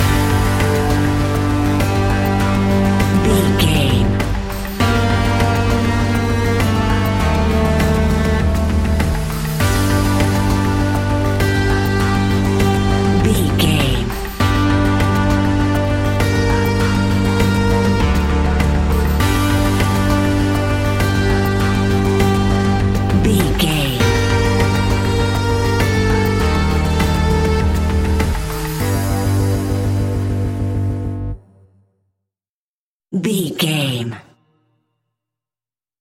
Aeolian/Minor
scary
ominous
dark
eerie
synthesiser
percussion
bass guitar
strings
electronic music
electronic instrumentals